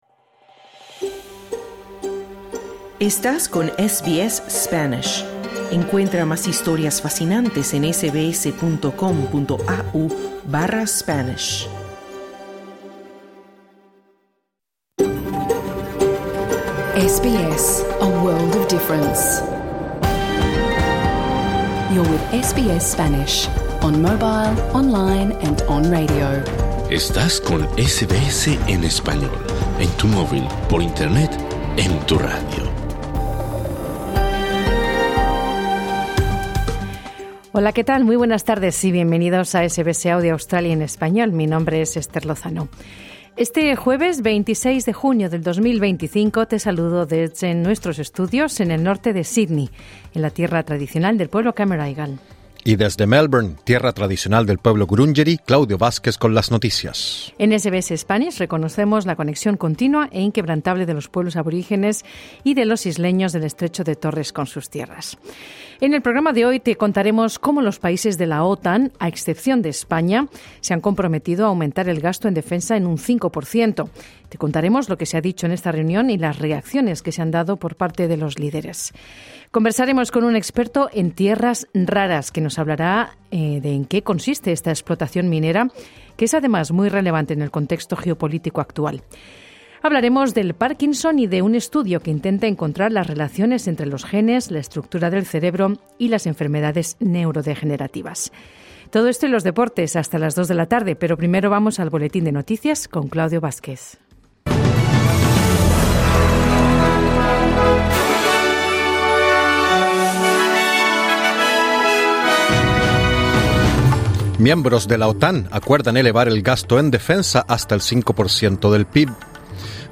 Los países de la OTAN han acordado aumentar el gasto en defensa al cinco por ciento del PIB, excepto España; te contamos cuál fue la reacción del presidente estadounidense Donald Trump. Además, conversamos con un experto en explotación de minerales raros. Y también hablamos sobre párkinson y de un estudio que intenta encontrar la relación entre los genes, la estructura del cerebro y las enfermedades neurodegenerativas.